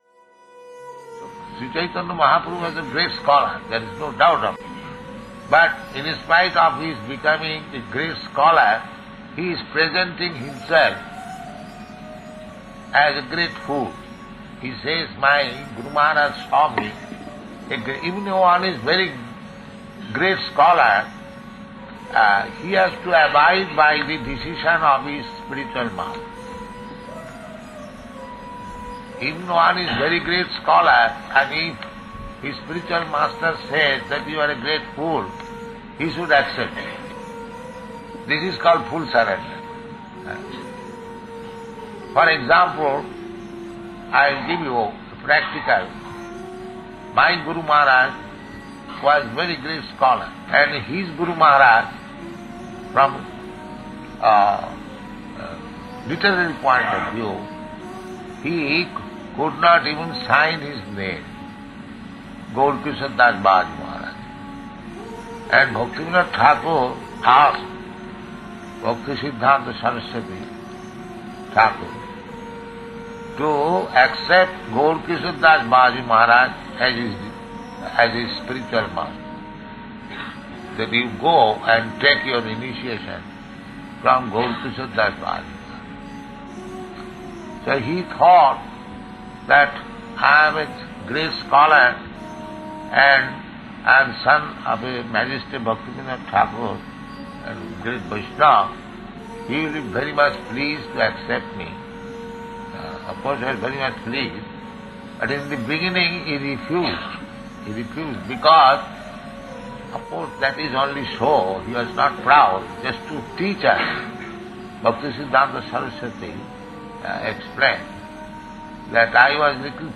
(690207 - Lecture Festival Appearance Day, Bhaktisiddhanta Sarasvati - Los Angeles)